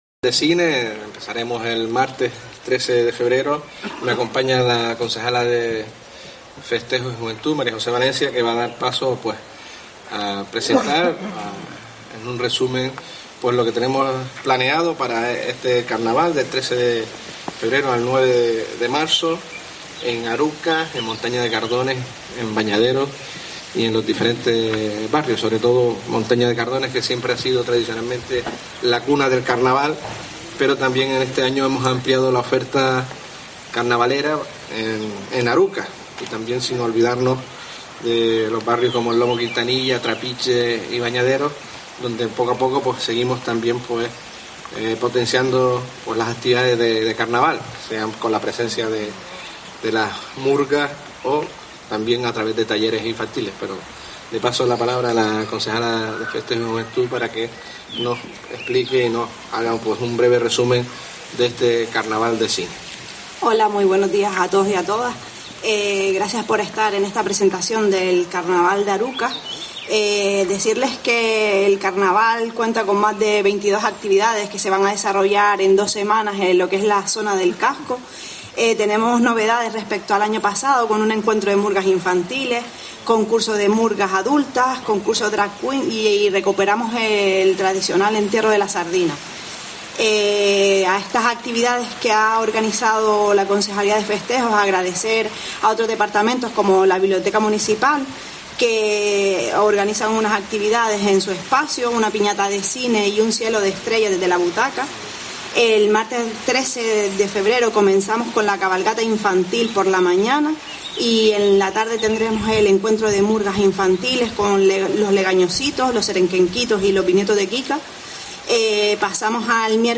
Juan Jesús Facundo, alcalde de Arucas y María José Valencia, concejala de Festejos